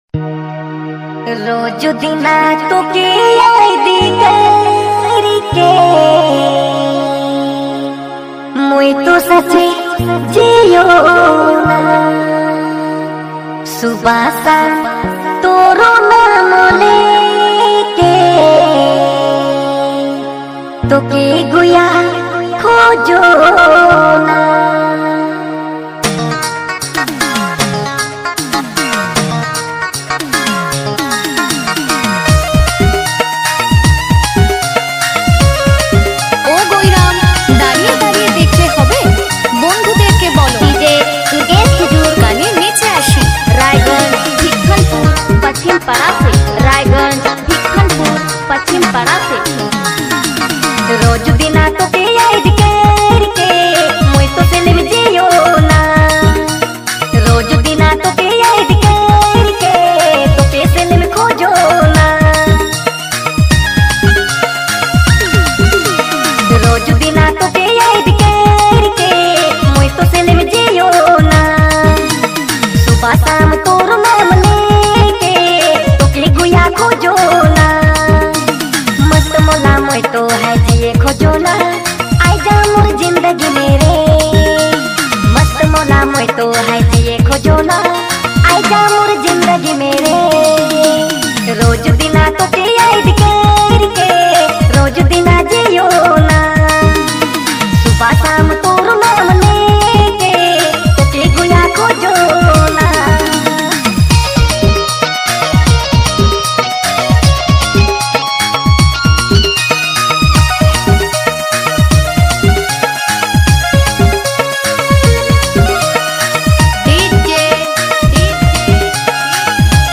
Remix Nagpuri Dj Songs Mp3 2022